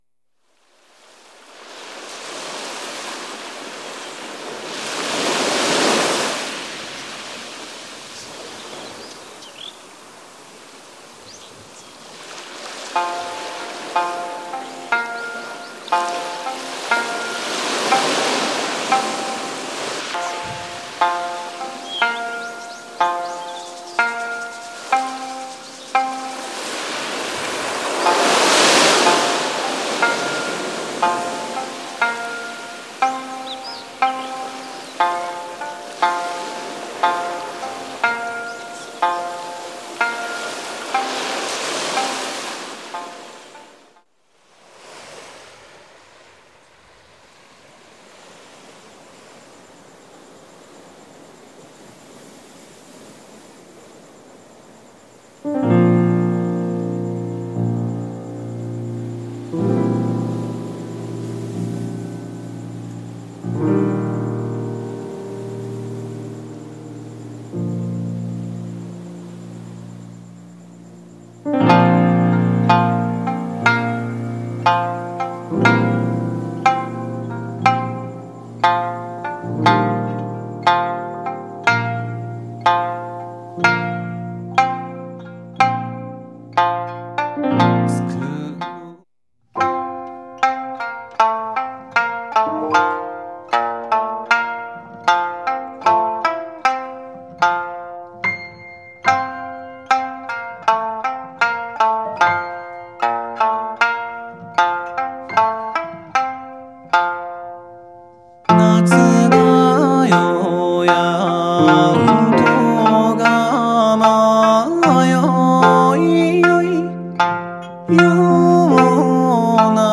Beautiful album of sanshin and vocals
accompanied by piano
mainly Okinawan traditional tunes in a very Okinawan style